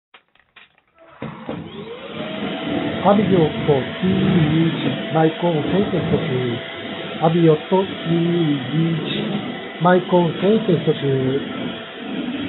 マイク性能は少しこもり気味
騒音でも声は聞こえるが少しこもったような声になっています。
✅掃除機使用中（60～70dB)